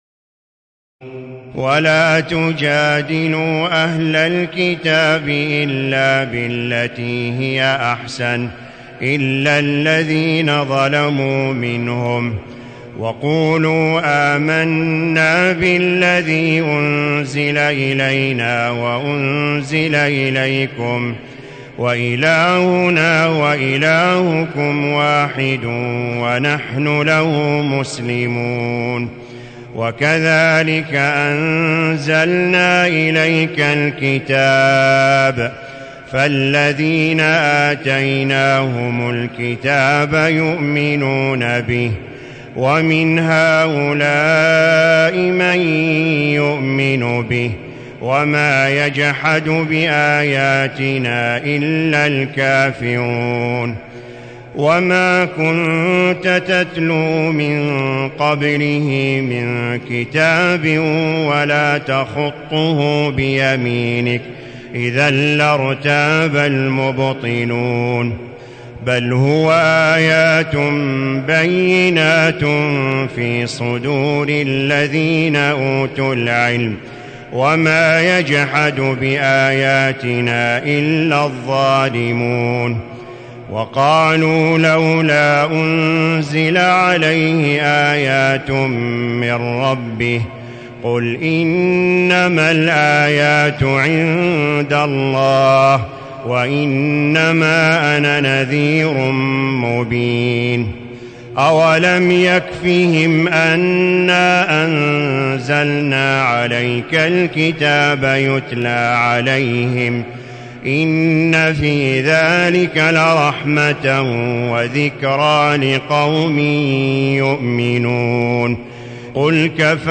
تراويح الليلة العشرون رمضان 1437هـ من سور العنكبوت (46-69) و الروم و لقمان (1-19) Taraweeh 20 st night Ramadan 1437H from Surah Al-Ankaboot and Ar-Room and Luqman > تراويح الحرم المكي عام 1437 🕋 > التراويح - تلاوات الحرمين